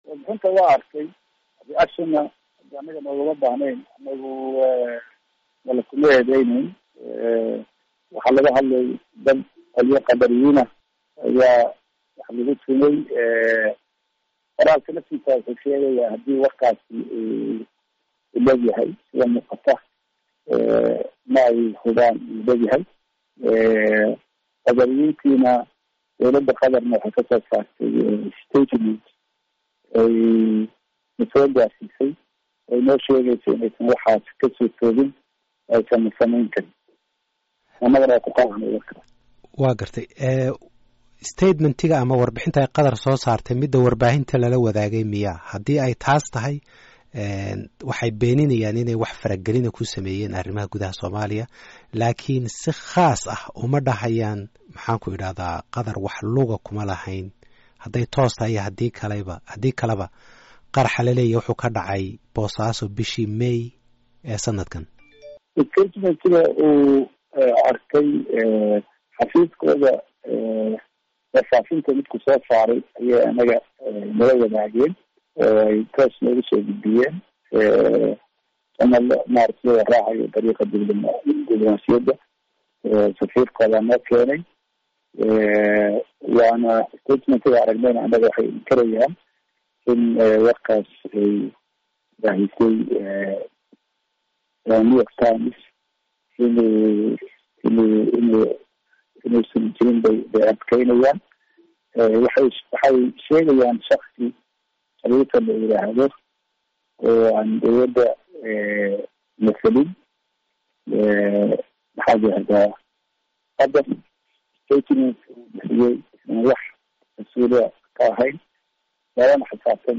Wasiirka arrimaha dibedda Soomaaliya, Axmed Ciise Cawad oo wareysi siiyey VOA ayaa sheegay inay arrintan kala hadleen dowladda Qatar ayna beenisay.